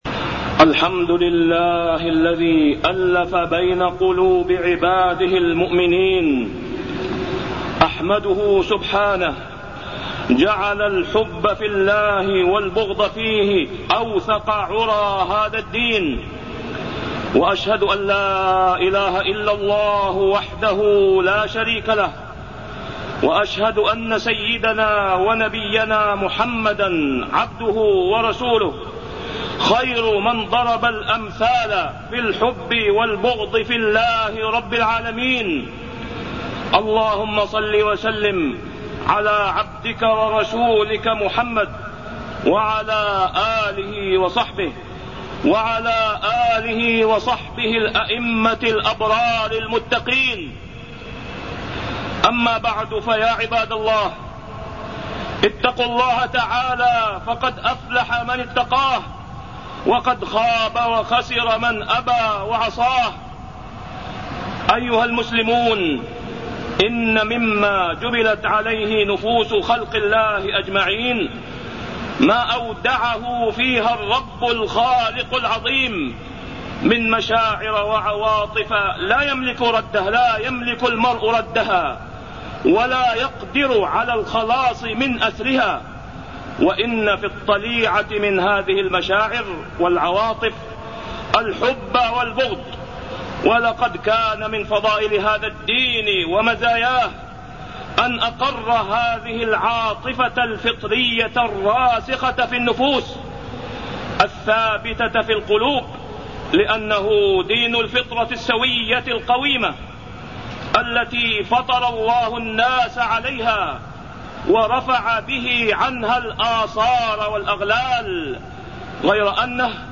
تاريخ النشر ١٤ شعبان ١٤٢١ هـ المكان: المسجد الحرام الشيخ: فضيلة الشيخ د. أسامة بن عبدالله خياط فضيلة الشيخ د. أسامة بن عبدالله خياط الحب في الله والبغض فيه